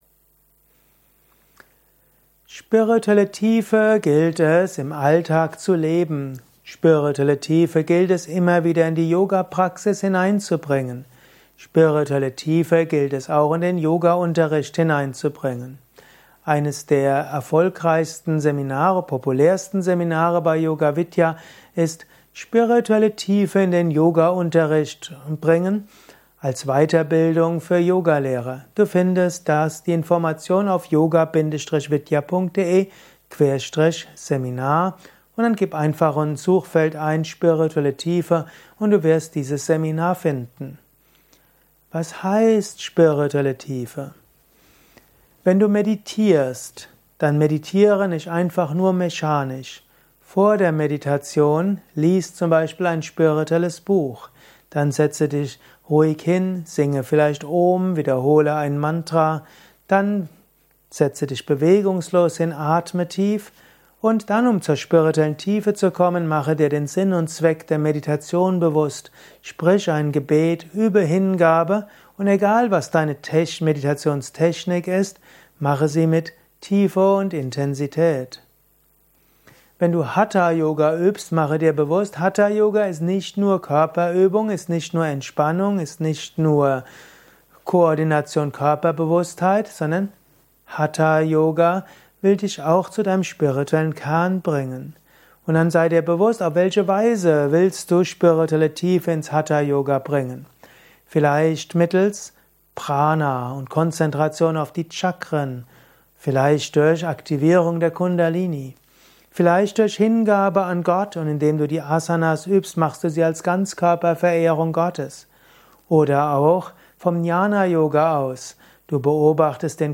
Kurzes Vortragsvideo über Spirituelle Tiefe :
Spirituelle Tiefe Audio Vortrag